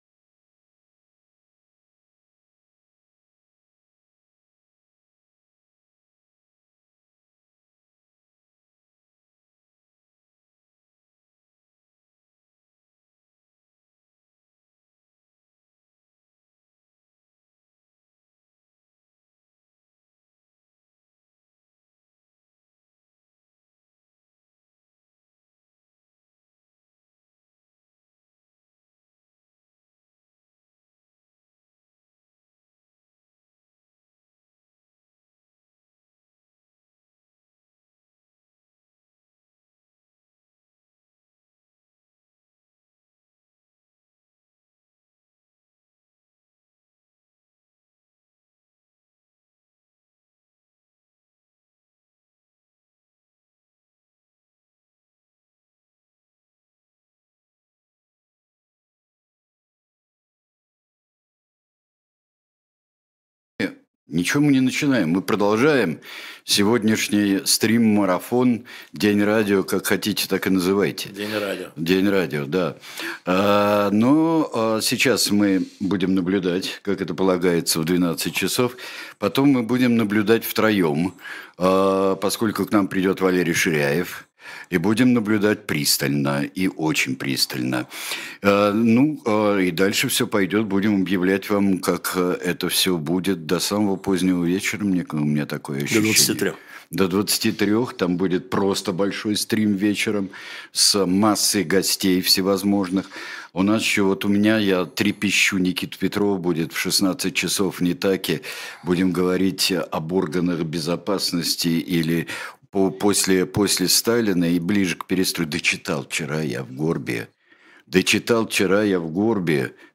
Ведущие Алексей Венедиктов и Сергей Бунтман.